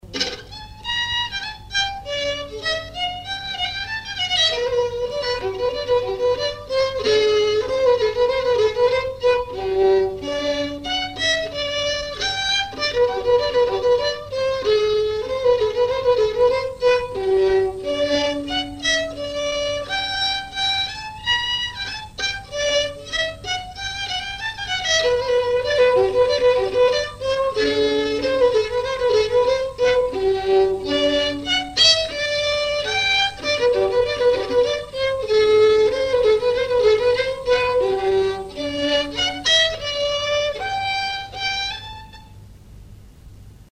Mémoires et Patrimoines vivants - RaddO est une base de données d'archives iconographiques et sonores.
danse : paskovia
enregistrements du Répertoire du violoneux
Pièce musicale inédite